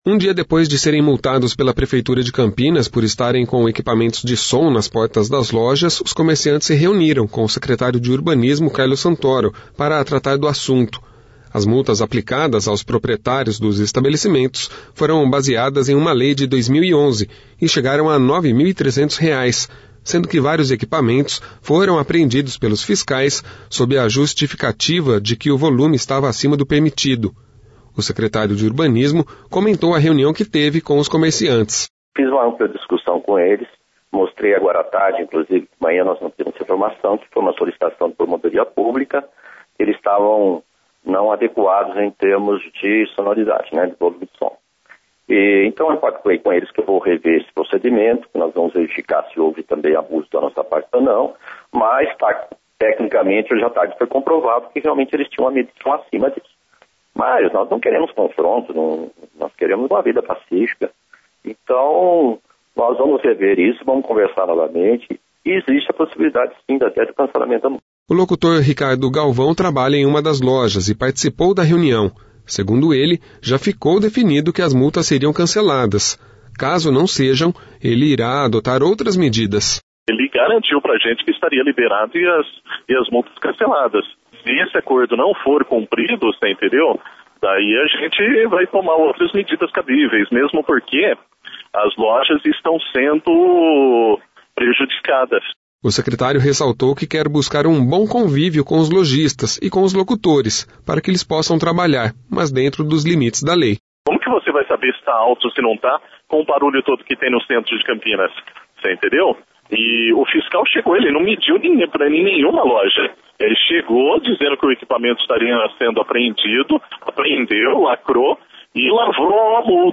O Secretario de Urbanismo comentou a reunião que teve com os comerciantes.